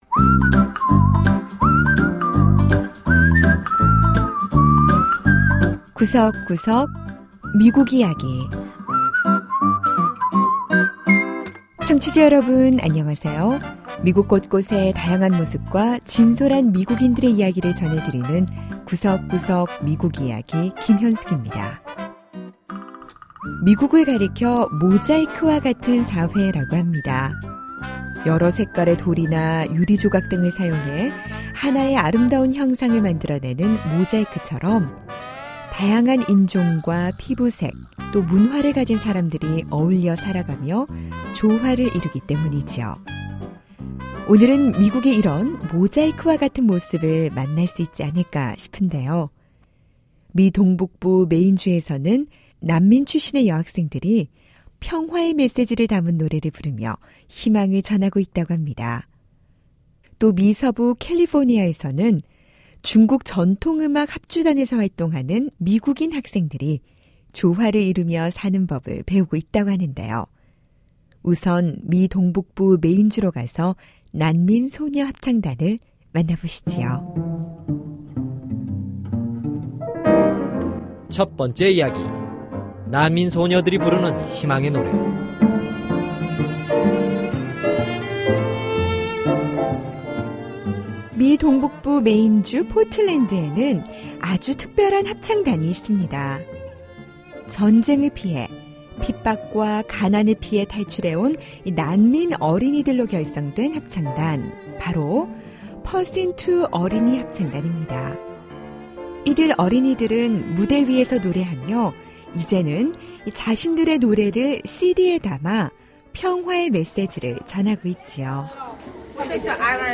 미 동북부 메인주의 ‘퍼신투 합창단’ 소녀들이 부르는 희망의 노래를 들어봅니다. 그런가 하면 미 서부 오클랜드의 차이나타운에서는 중국 전통음악 합주단에서 미국인 학생 두 명이 두각을 나타내고 있다고 합니다.